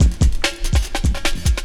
16 LOOP01 -R.wav